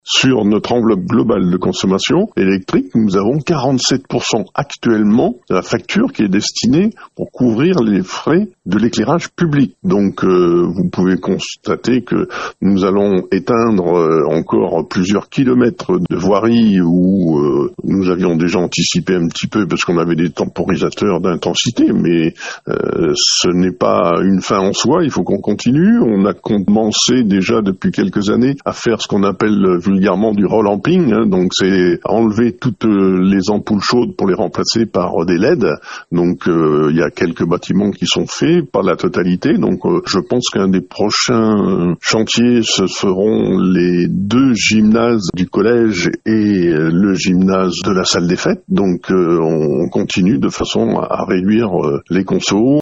On écoute le maire Eric Authiat :